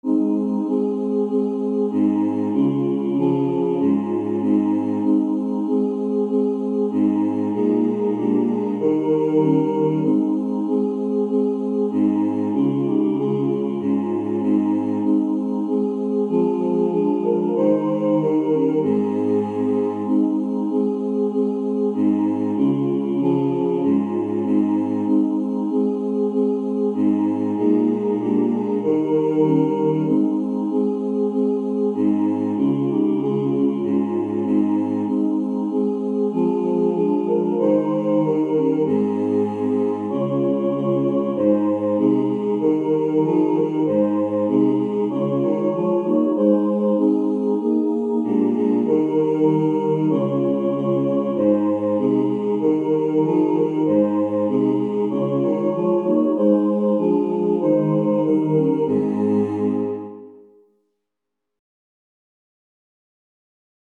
Voicing/Instrumentation: SATB , SATB quartet
A Cappella/Optional A Capella